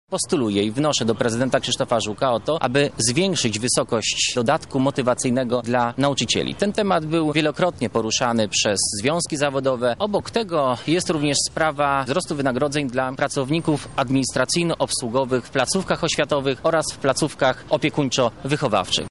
-mówi Tułajew